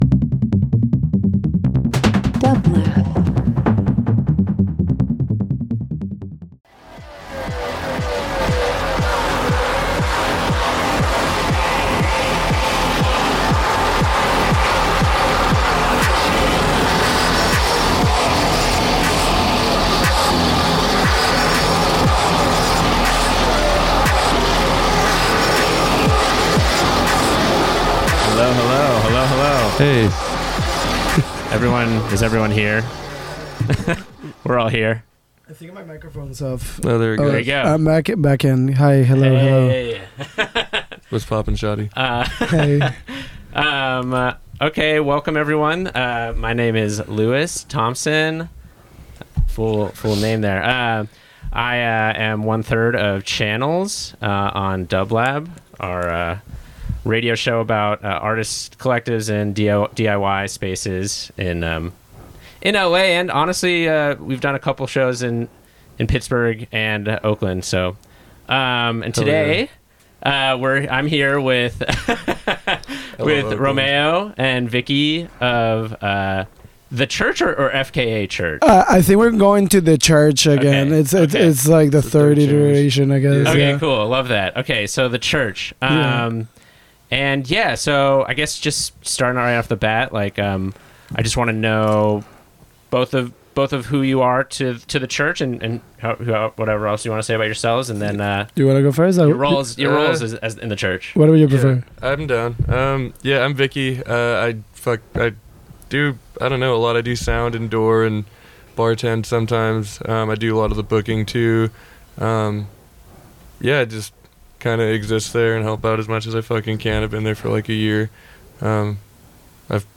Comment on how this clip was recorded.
Featuring a curated mix of music in each episode, the show seeks to connect listeners to a particular place, time, and creative community.